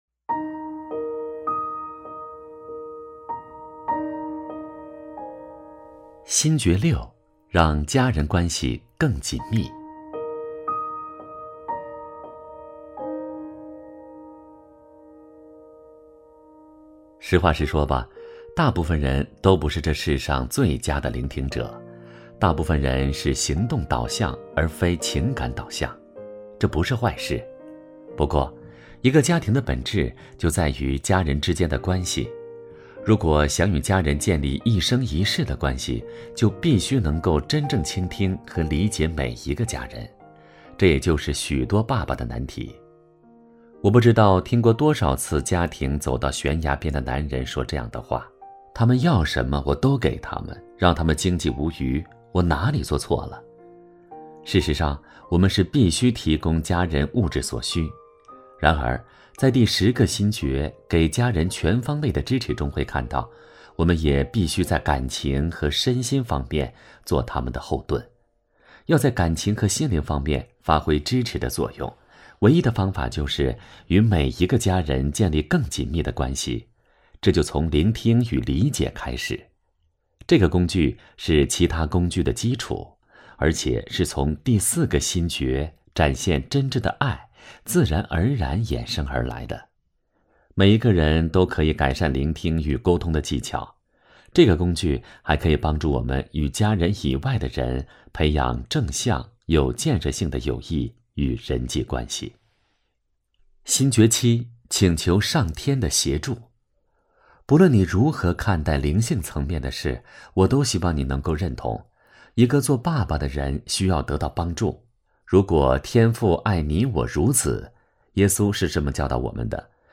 首页 > 有声书 > 婚姻家庭 | 成就好爸爸 | 有声书 > 成就好爸爸：08 认识当好爸爸的十个心诀（下）